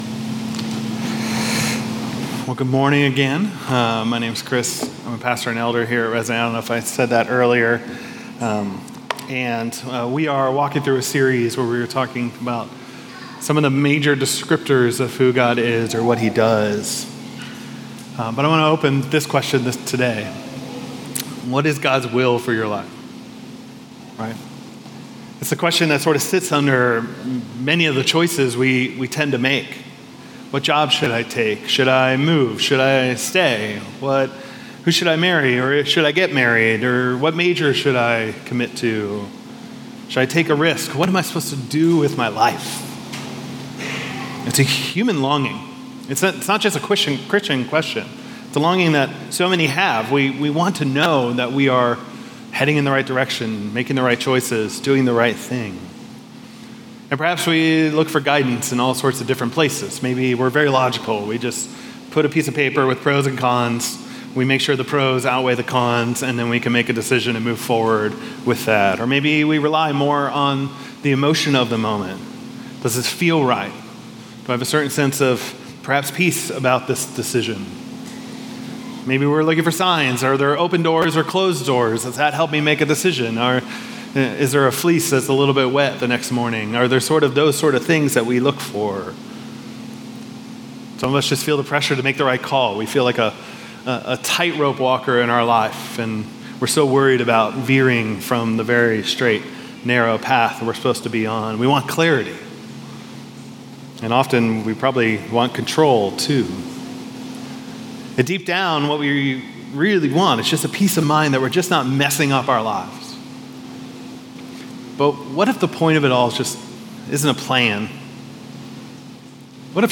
All Sermons The God Who…